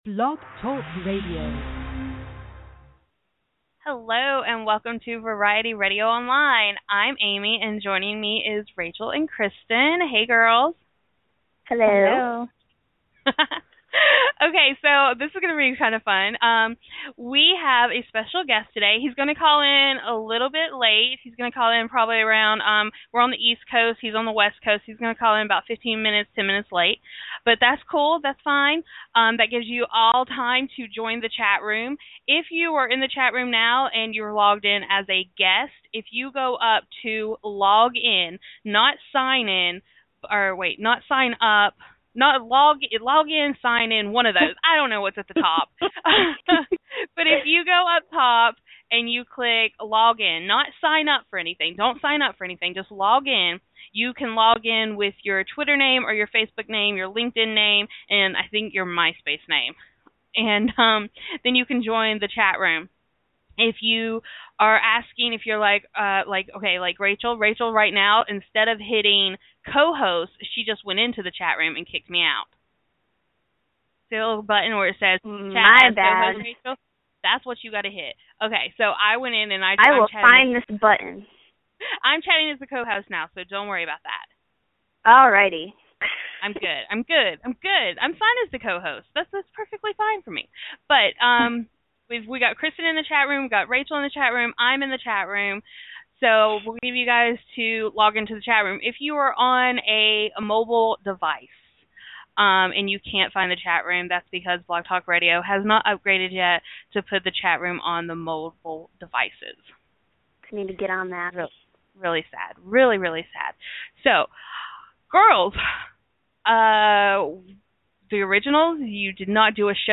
Chase Coleman is calling into Variety Radio Online to answer all of your questions LIVE on air..